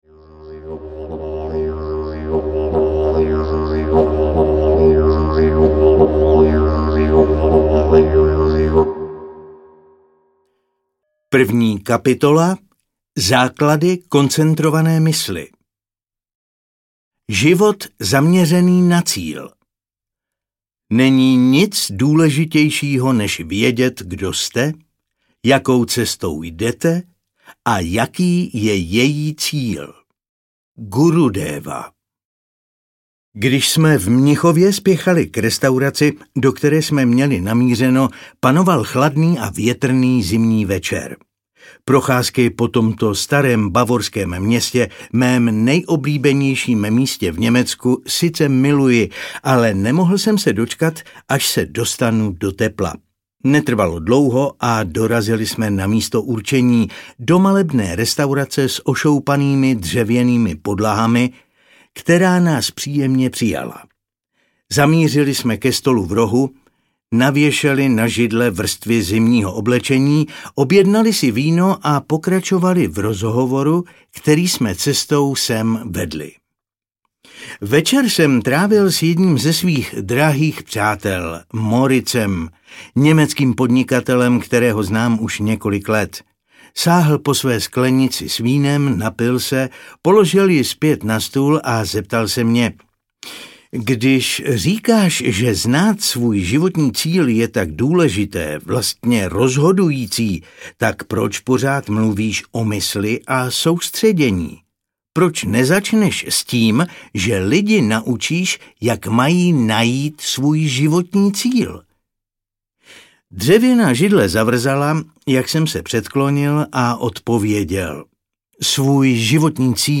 Moc soustředěné pozornosti audiokniha
Ukázka z knihy